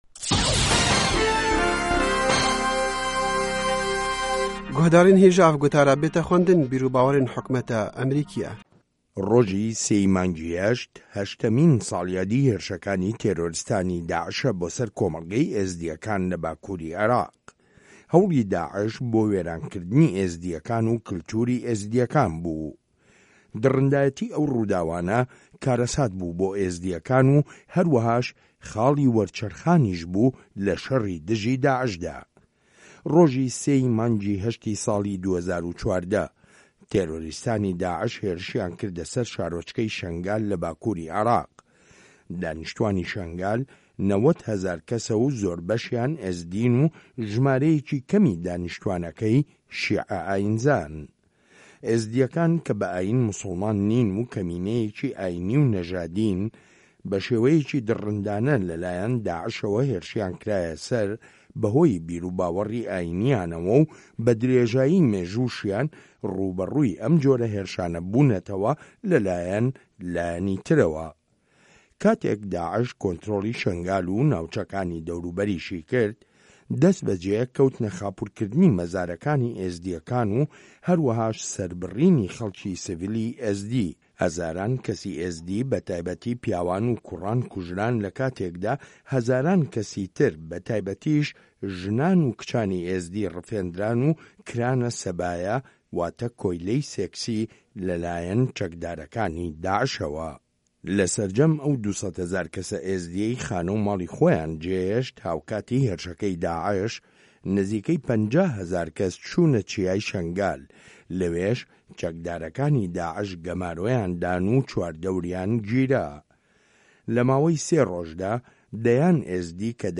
سه‌رگوتار